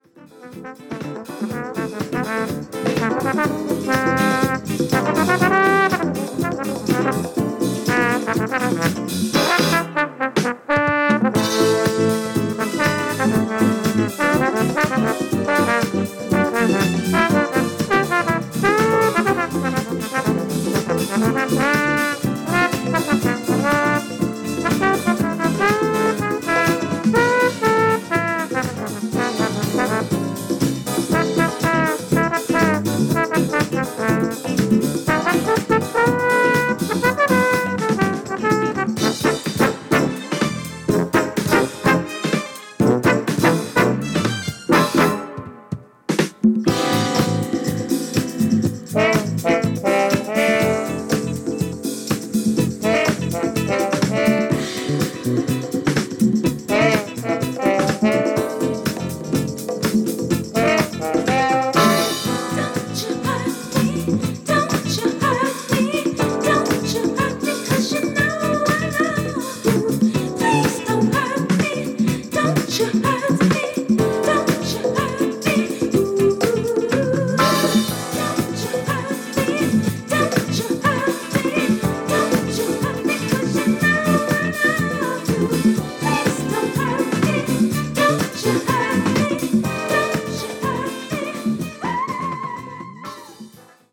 この頃はFunkyですね～。